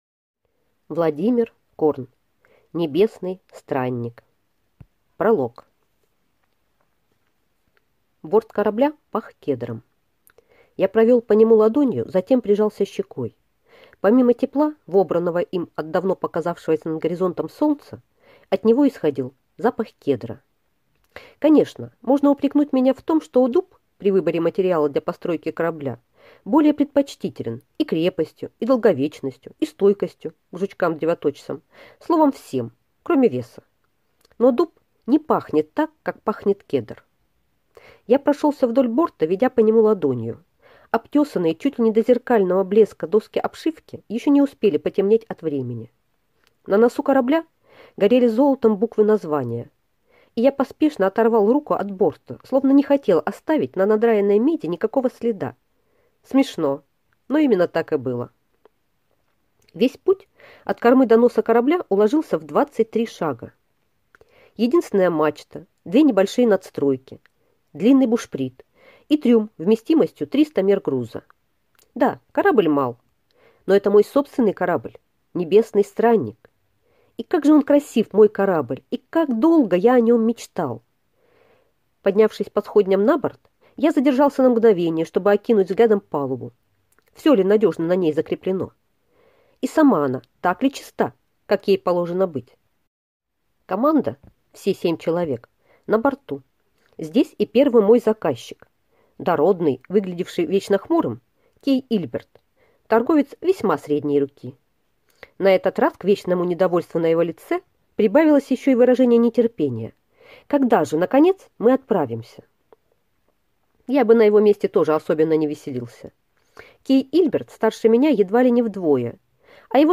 Аудиокнига Небесный странник | Библиотека аудиокниг